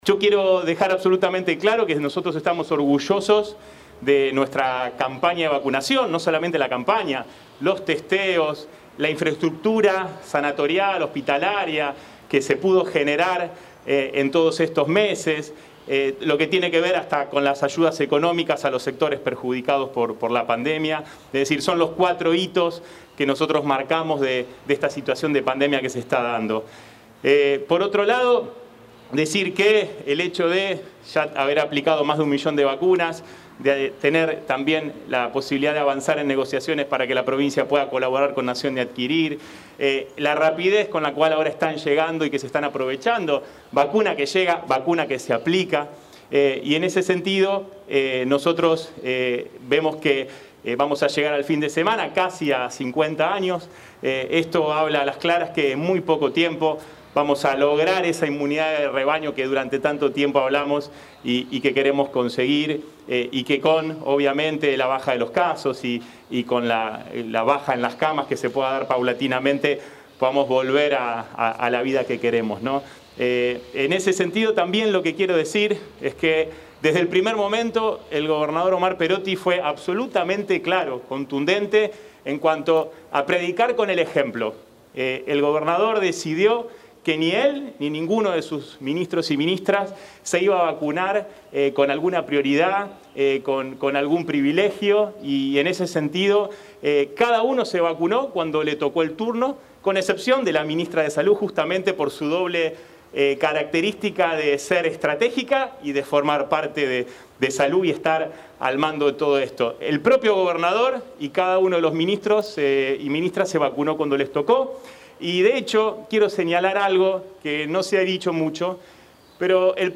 Sukerman - Conferencia de prensa